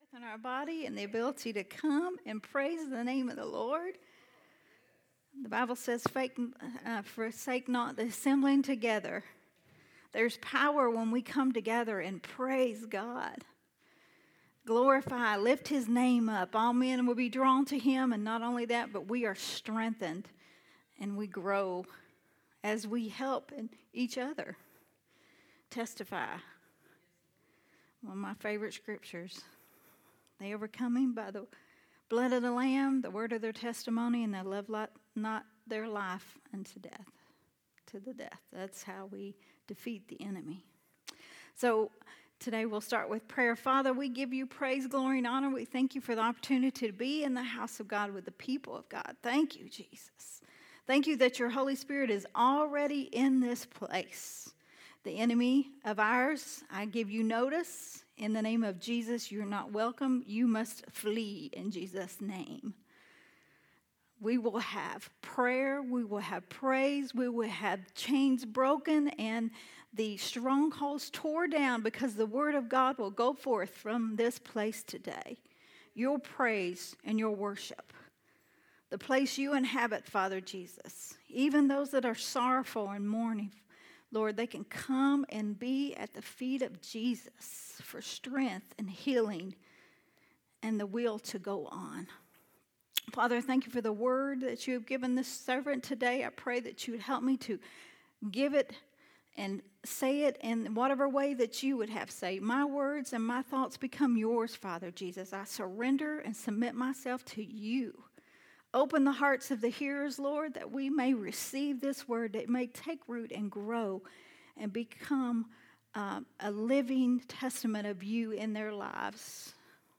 a Sunday Morning Refreshing
recorded at Unity Worship Center